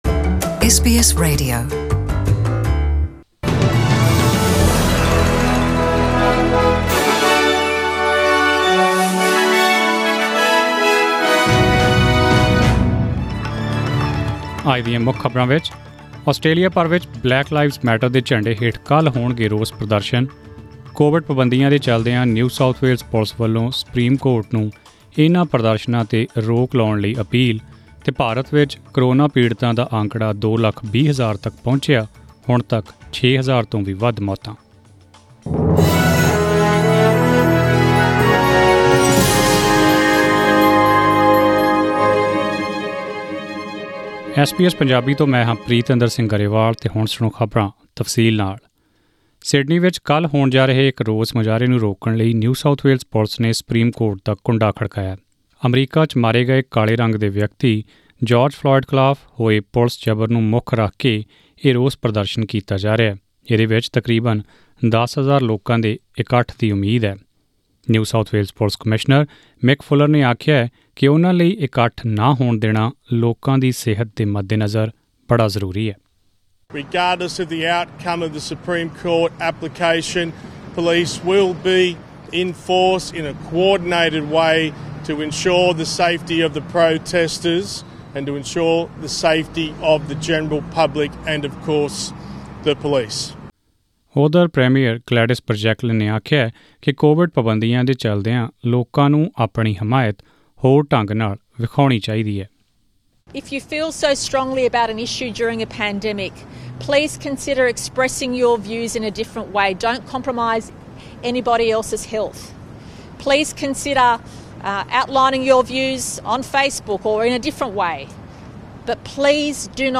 Australian News in Punjabi: 5 June 2020